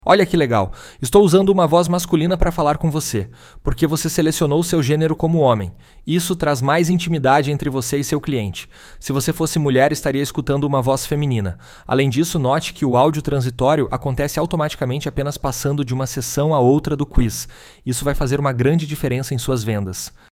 olha-que-legal-homem.mp3